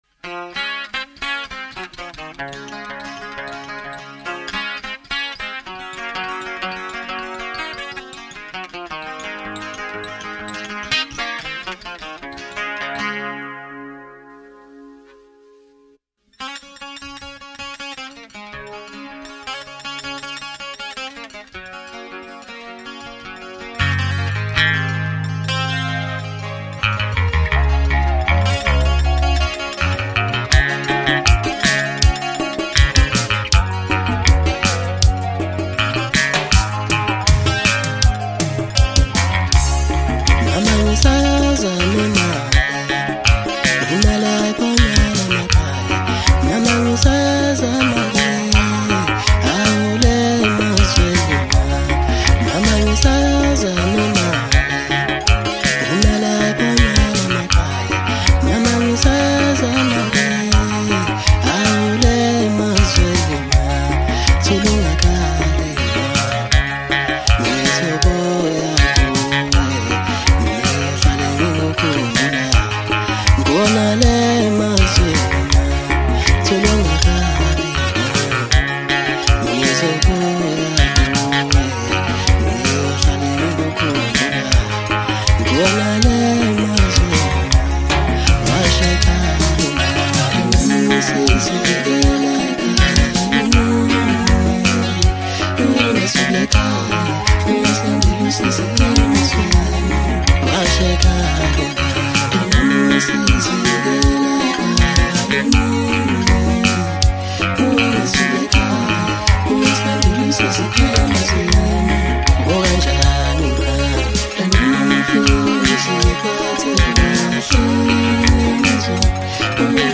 Home » Hip Hop » DJ Mix » Maskandi
South African singer-songsmith